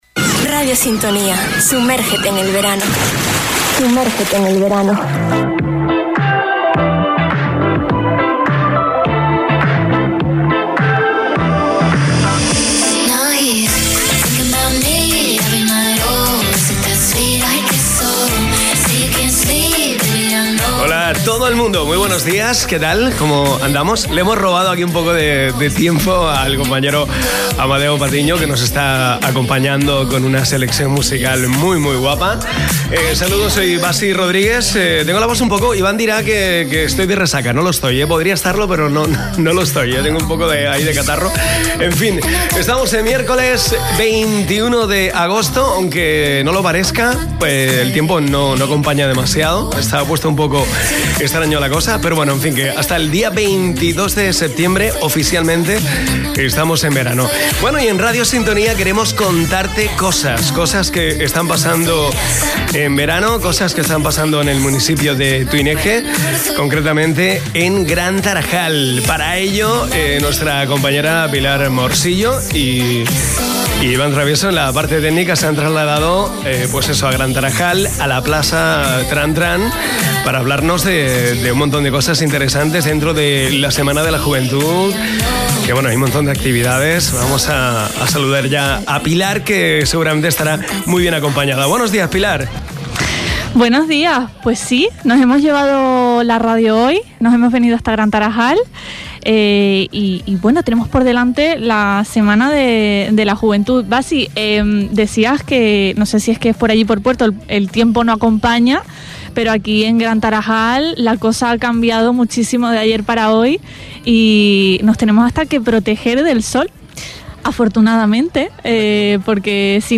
A punto del medio siglo, la Semana de la Juventud de Gran Tarajal cumple 49 años y, para la ocasión, hoy trasladamos la radio hasta la avenida de la localidad. Unos años que dejan anécdotas que contar a las personas con las que hemos hablado esta mañana, desde la parte administrativa como de participación ciudadana.
Entrevistas